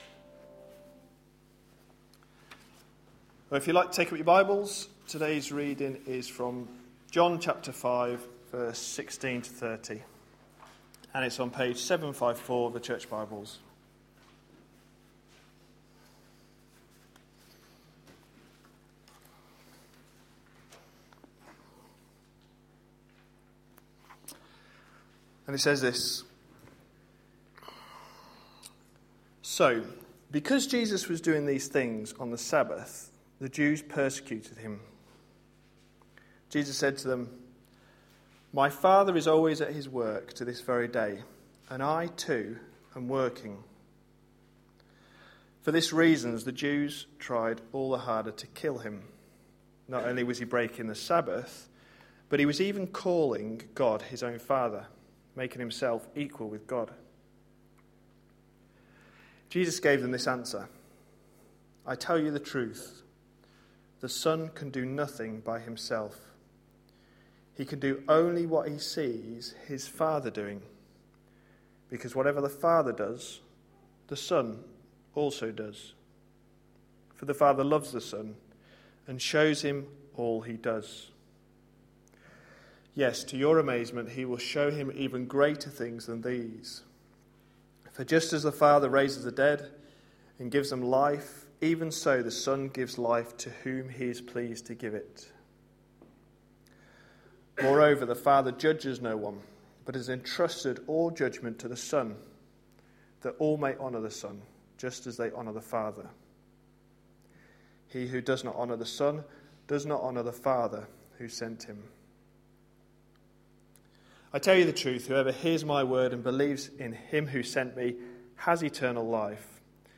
A sermon preached on 13th January, 2013, as part of our The gospel is the reason series.